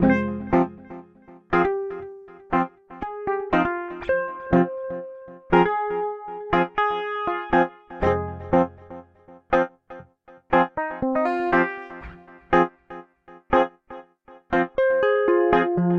雷盖吉他
描述：妙趣横生
标签： 120 bpm Reggae Loops Guitar Electric Loops 2.69 MB wav Key : B
声道立体声